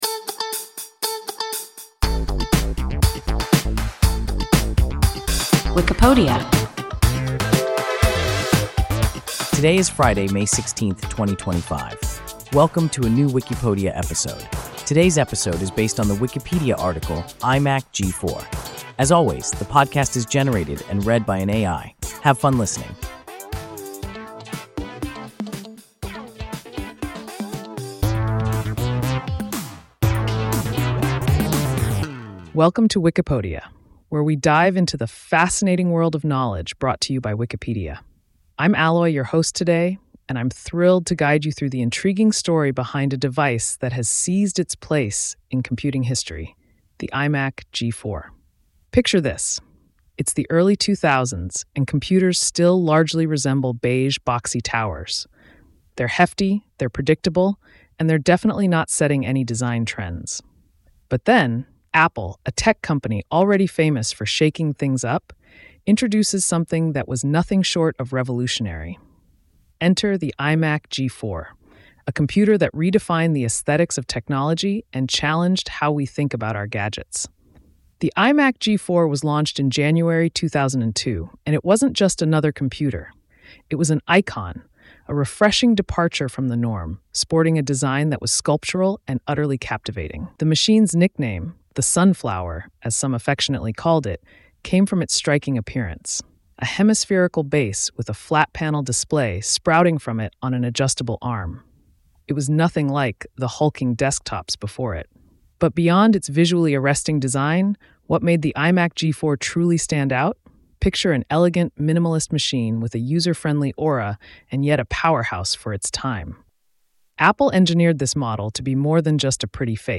IMac G4 – WIKIPODIA – ein KI Podcast